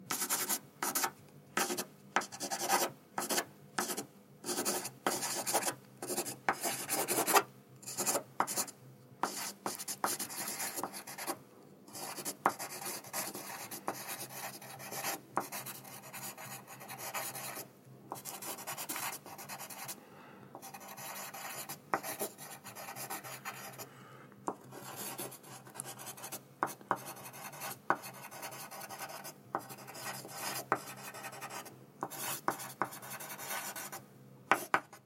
铅笔写
描述：wav文件44.1 kHz 16位。单。低调100分贝
Tag: 书写 绘画 铅笔